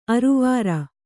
♪ aruvāra